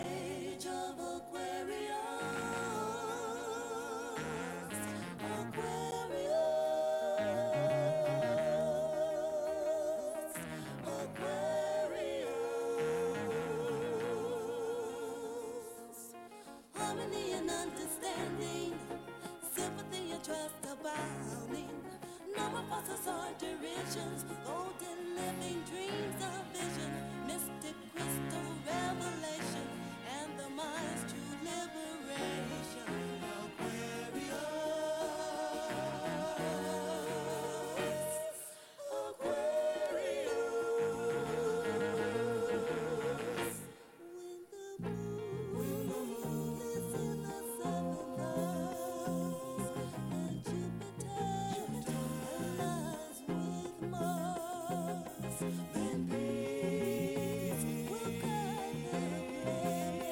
psychedelic soul